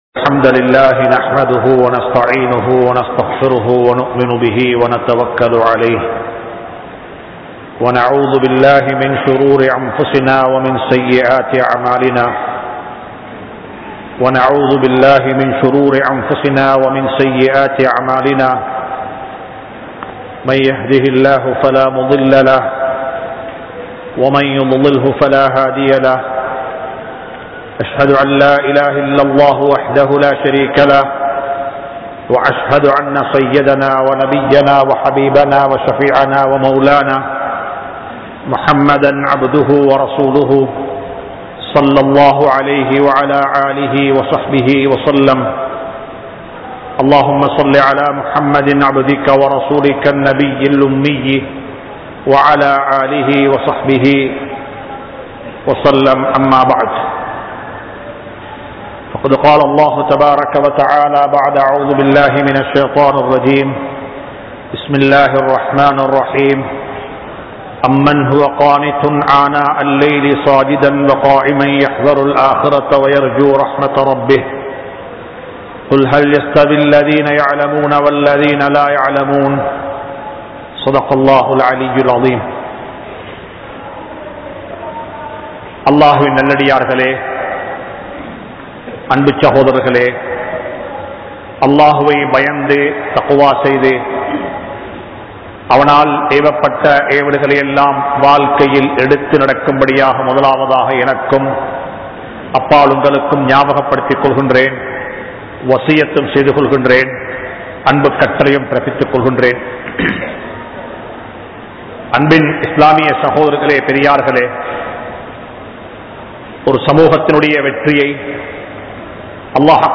Padaithavanai Nambugal(Believe The Creator) | Audio Bayans | All Ceylon Muslim Youth Community | Addalaichenai
Grand Jumua Masjith